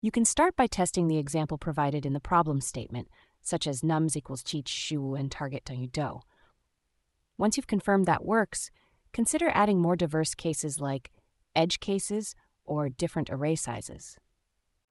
Text to speech read out number in wrong language
After some testing, we confirmed that for the following text input, the model incorrectly reads numbers in Chinese.
tts_test_en-US-NovaMultilingualNeural.wav